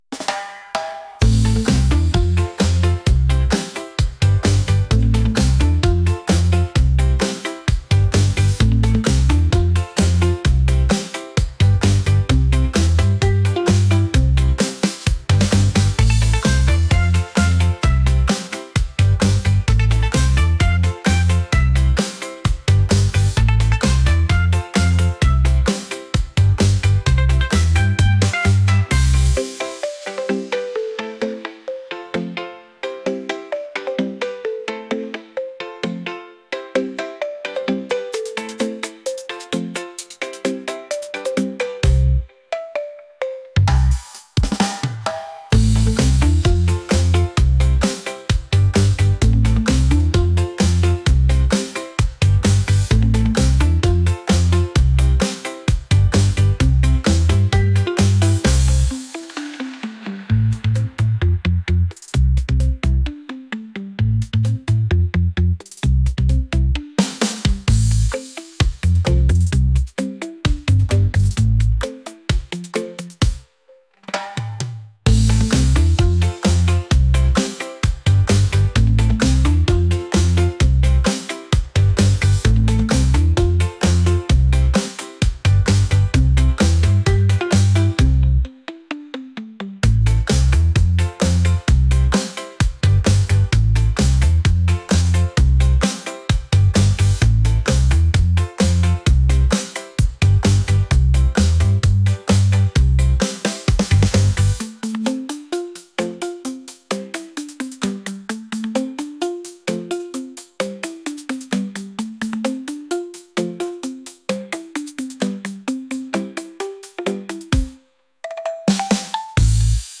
upbeat | reggae